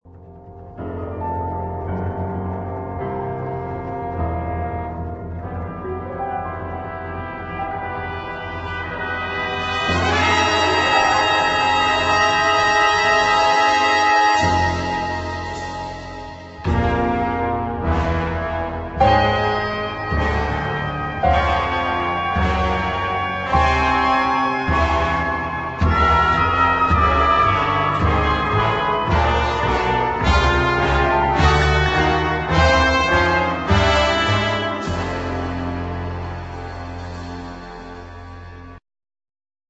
1992 thrilling slow instr.